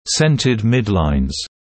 [‘sentəd ‘mɪdlaɪnz][‘сэнтэд ‘мидлайнз]поставленные, расположенные по центру средние линии (зубных рядов) (US centered midlines)